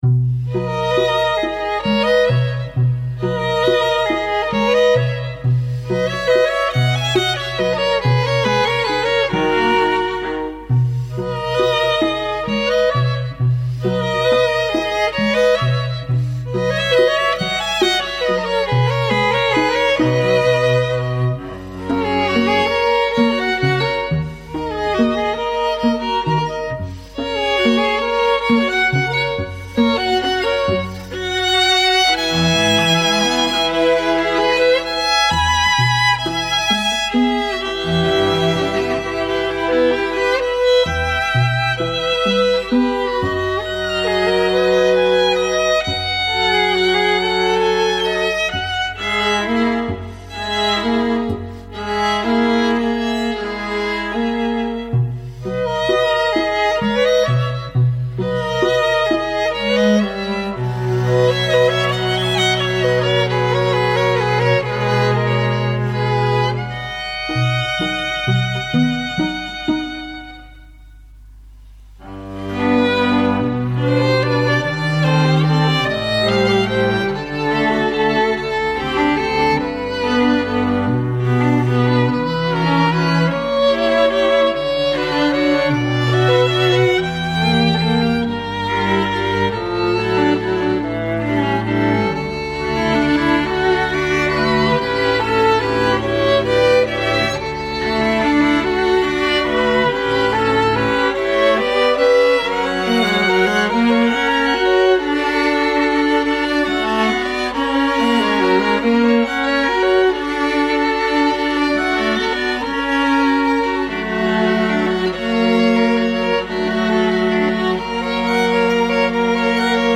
Guildhall String Quartet